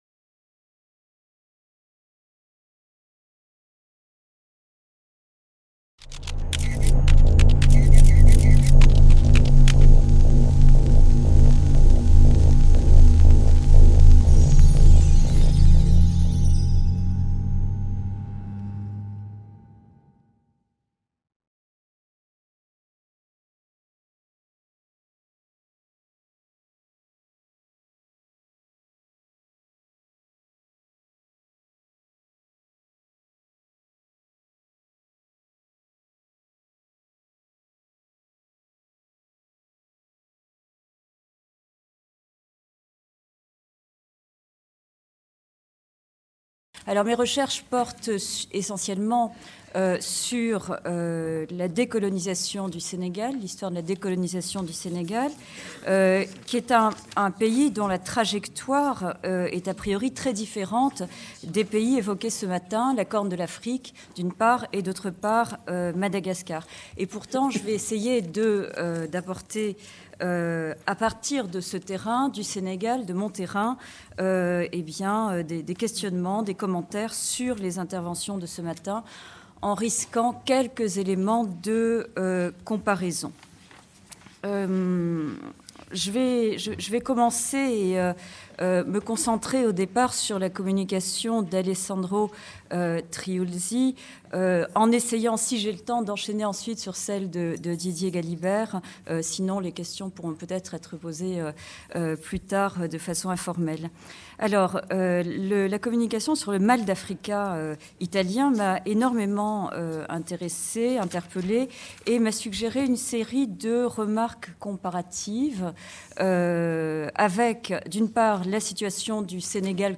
2/C Artisans de l’histoire et acteurs de l’Histoire/Discutants et débats/Il était une fois. Les indépendances africaines… La fin des empires ?